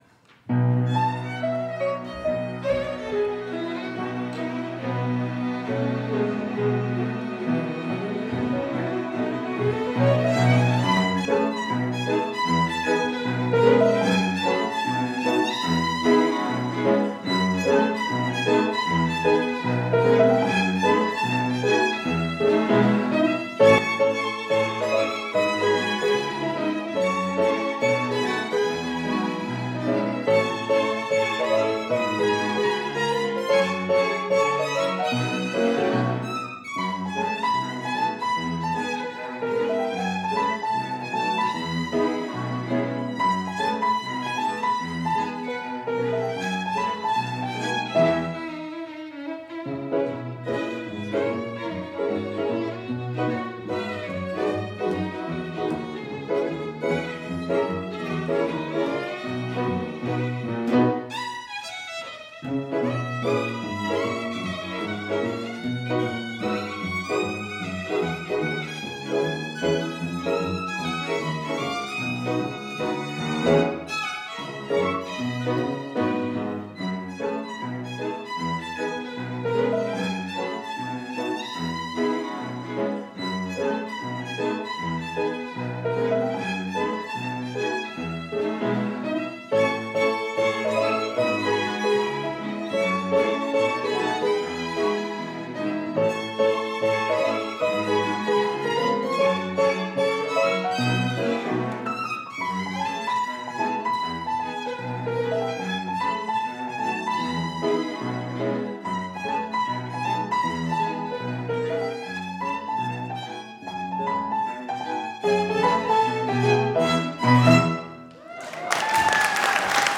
Instrumentation: 2 Violins, Piano
Category: Encore, Chamber Music
Ensemble: Trio
Instrument / Voice: Piano, Violin